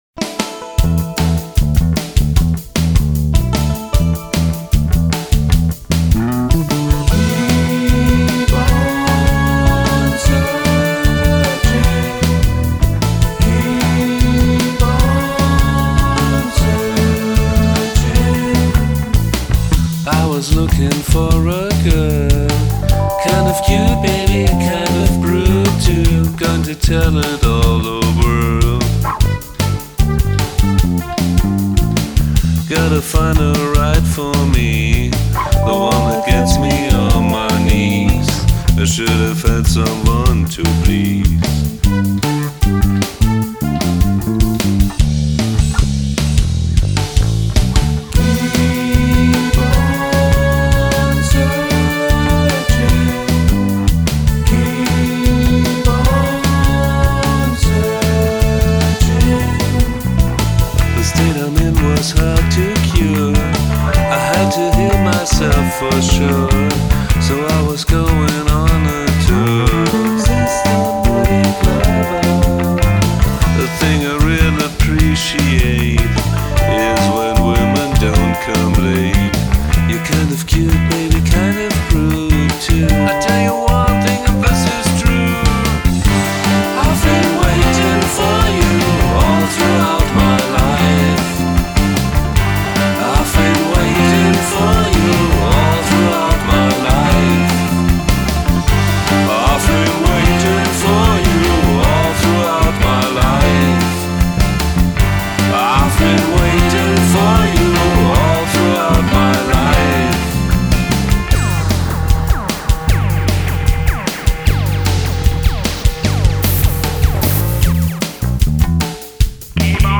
Alto Sax
All Other Instruments, Vocals & Programming